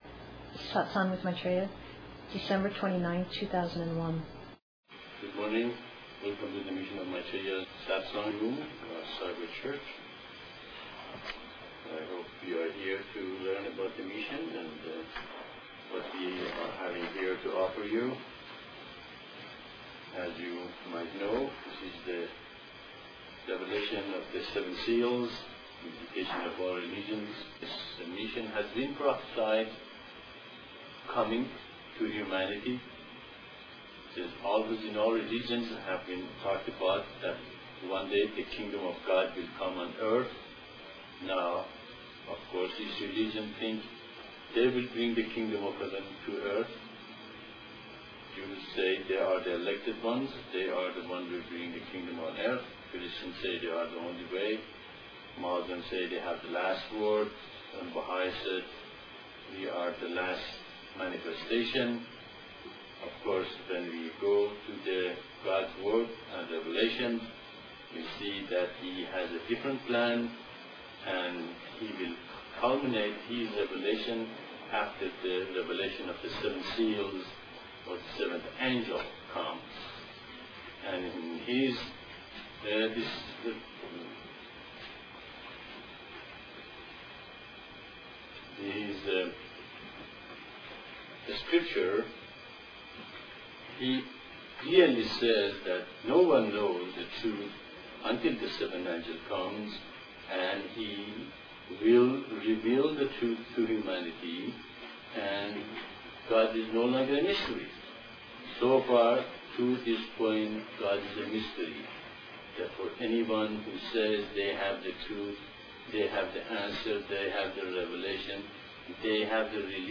Satsang (Discourse) 12/29/01